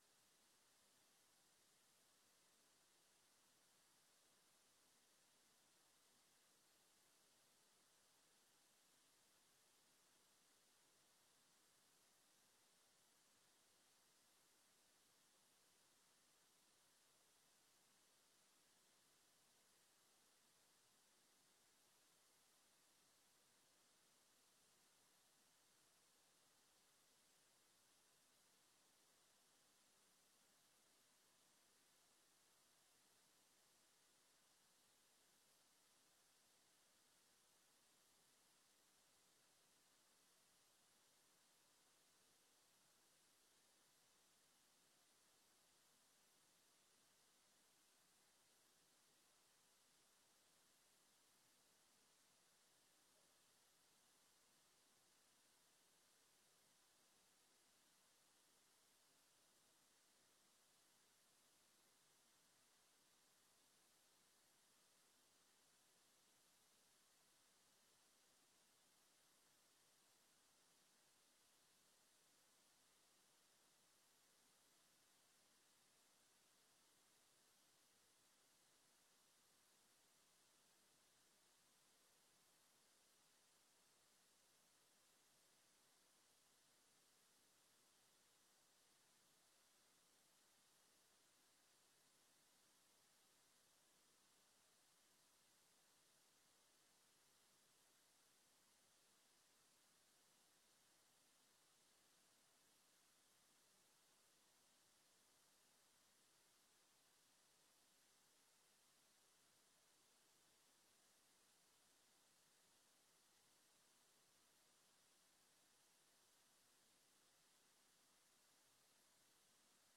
Raadscommissie Bestuur en Middelen 24 februari 2025 19:30:00, Gemeente Den Helder
Download de volledige audio van deze vergadering
Locatie: Trouwzaal